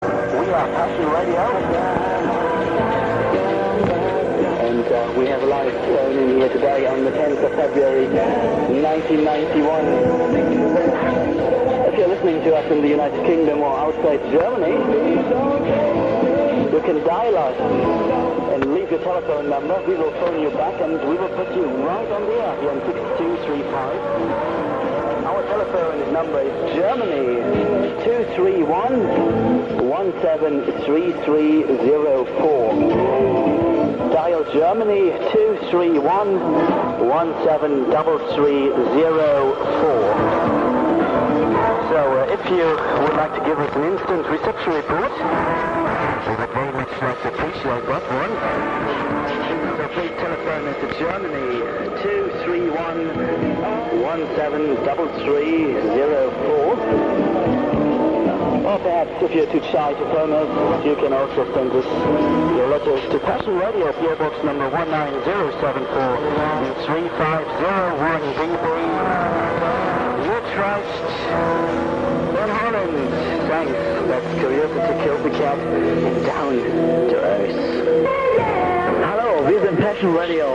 Passion Radio - Shortwave Pirate - Germany